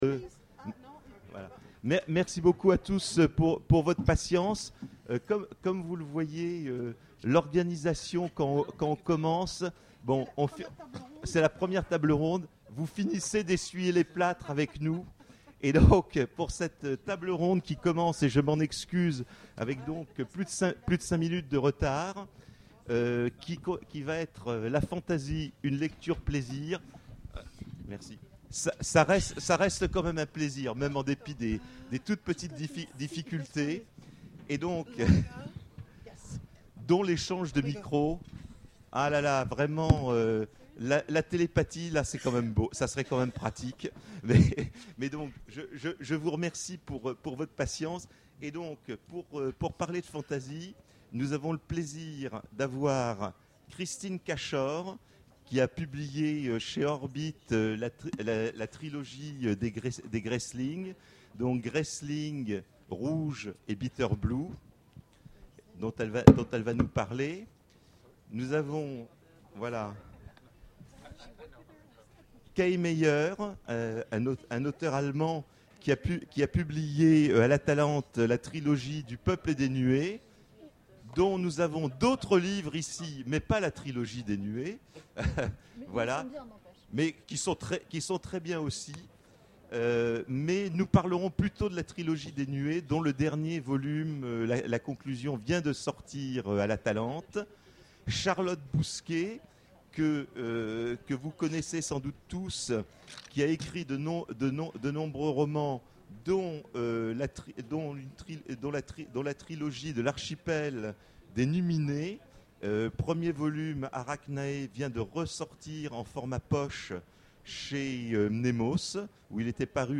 Imaginales 2013 : Conférence La Fantasy...